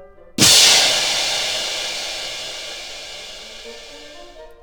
tarelki.wav